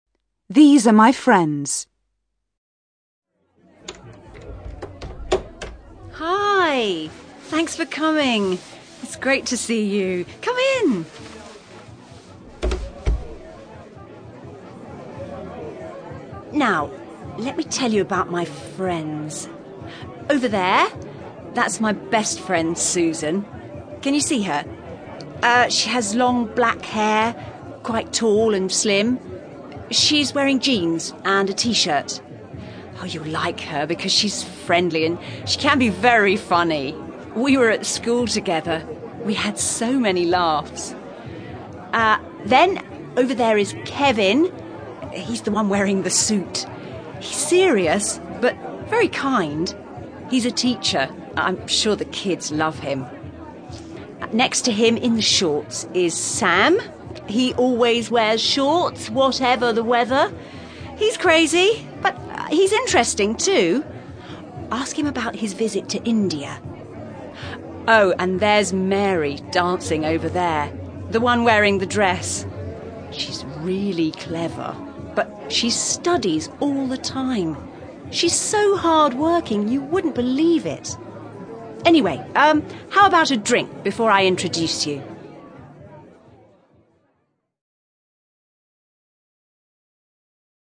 You're going to listen to a young woman pointing out her friends, Susan, Kevin, Sam and Mary at a party.